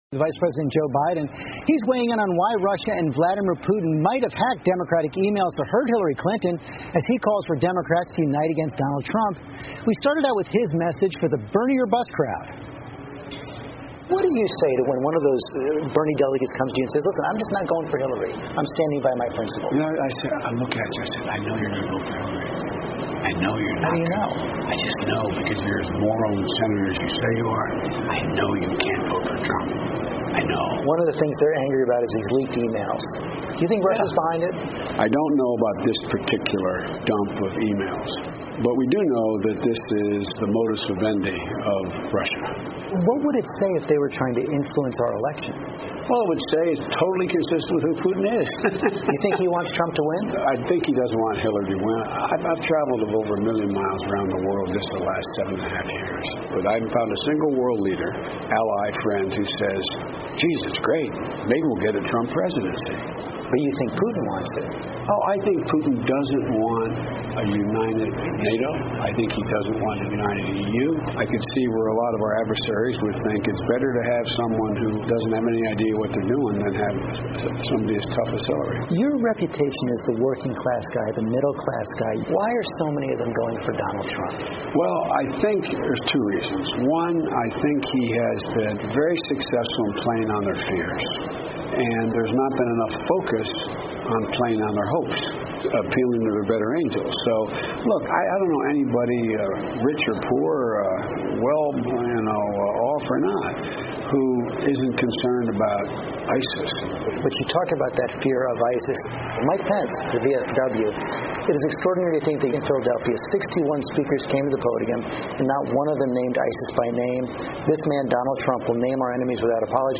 访谈录 国副总统拜登严词抨击特朗普 称其毫无头绪 视频已修复 听力文件下载—在线英语听力室